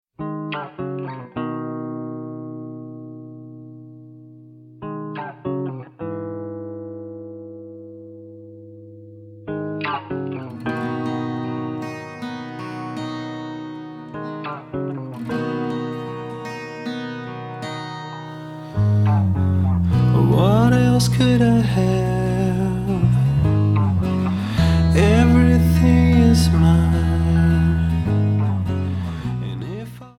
Alternative,Rock